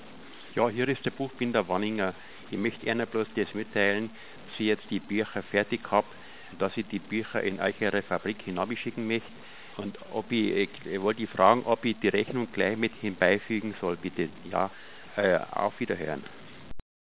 Bayrisch